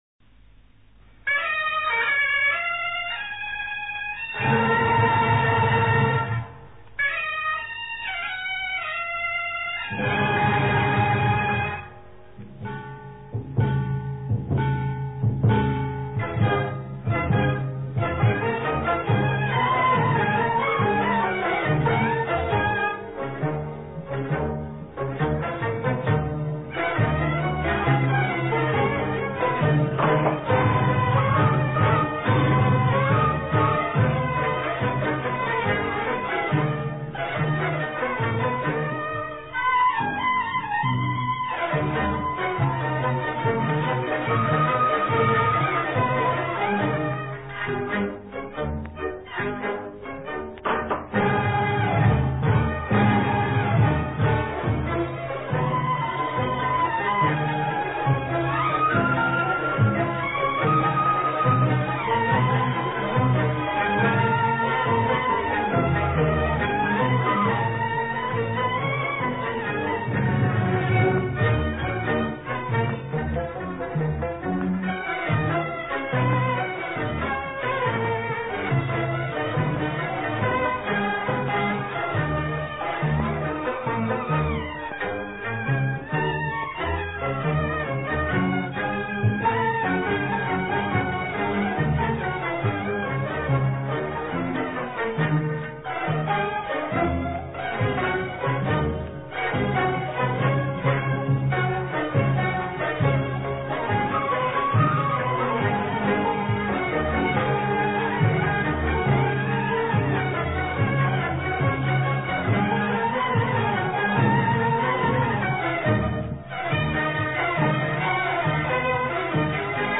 全曲節奏輕快。開頭以嗩吶吹出號召性的引子，表示比賽開始。接着是樂曲的主題，逐漸活躍，表現了龍舟競發，生氣勃勃的景象。
然後是比賽正式開始，情緒逐漸熱烈，以彈撥及拉弦跳動的音調，配合鏗鏘的鑼鼓節奏，加進了打擊樂，刻畫了龍舟在水上前進的形象。 隨後速度進一步加快，節奏多變，猶如龍舟你追我趕，氣氛熱鬧。
最後吹響嗩吶象征比賽結束，結尾再現了前一段的曲調，表現龍舟在碧波上自由蕩漾，令人心怡。